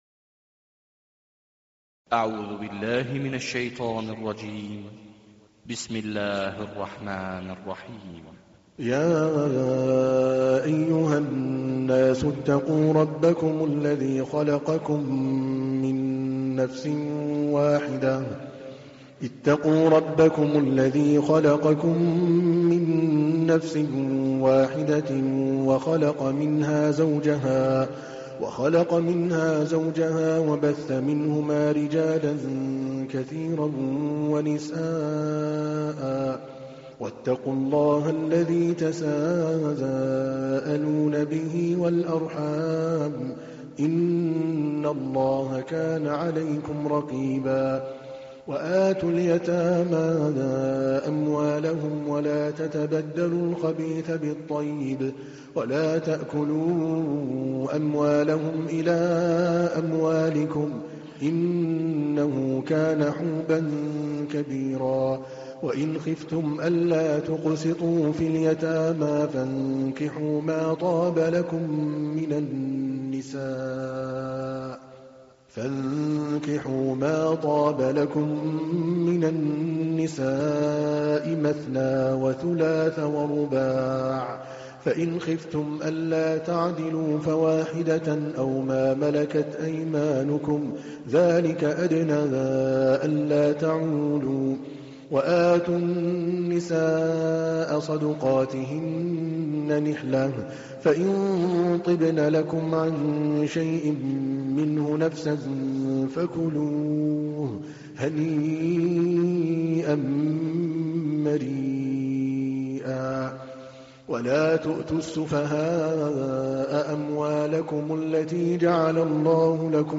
تحميل : 4. سورة النساء / القارئ عادل الكلباني / القرآن الكريم / موقع يا حسين